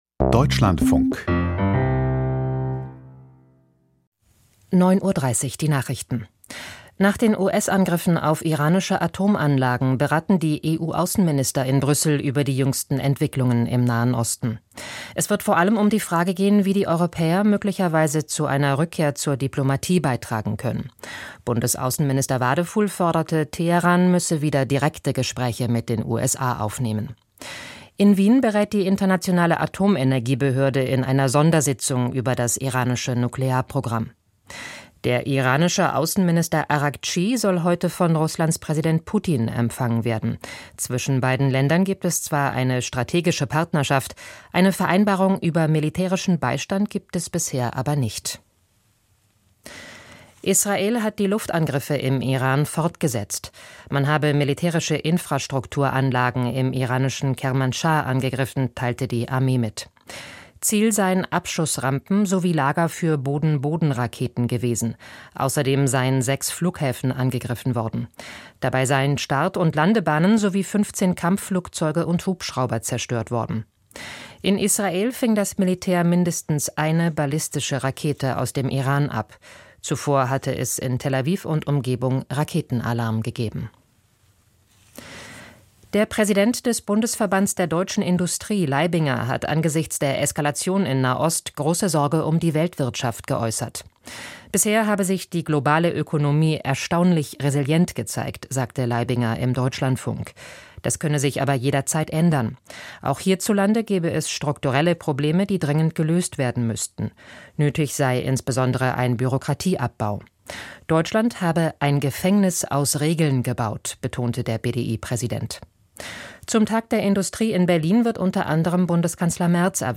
Die Nachrichten vom 23.06.2025, 09:30 Uhr